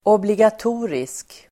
Uttal: [åbligat'o:risk]